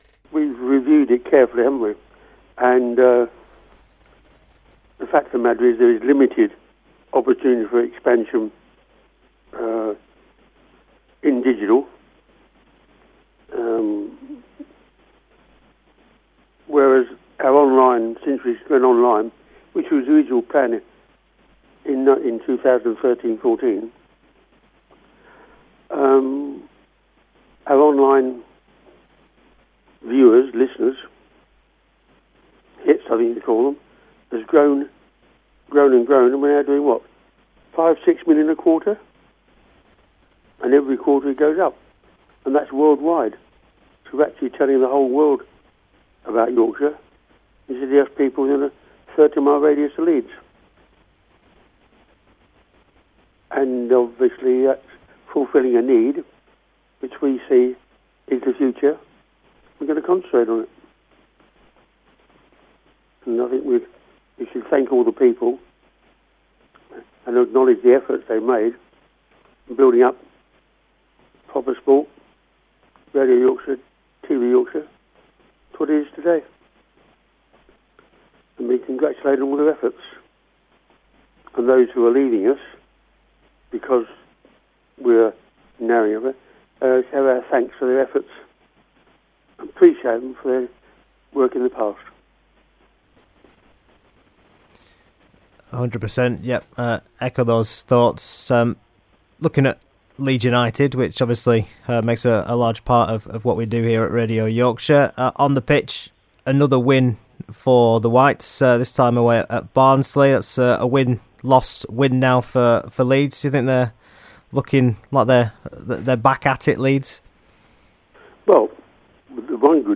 The Ken Bates Interview